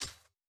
Impact on Sand.wav